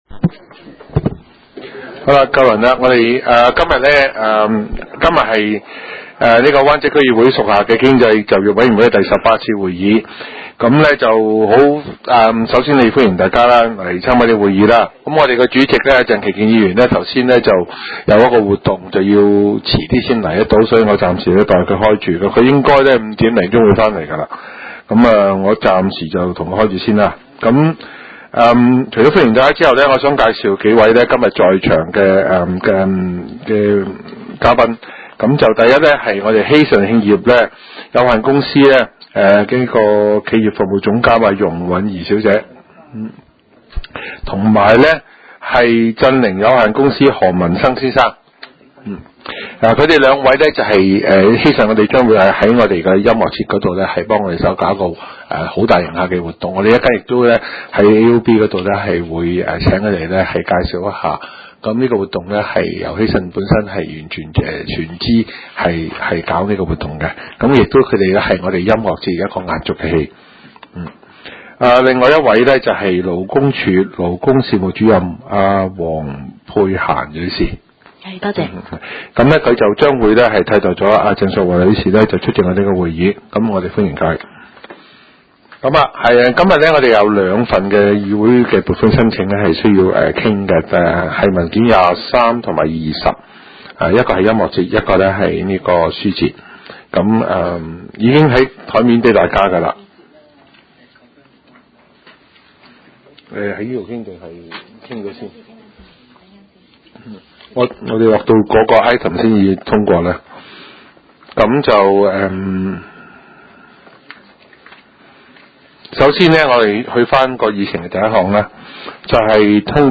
經濟及就業委員會第十八次會議
灣仔民政事務處區議會會議室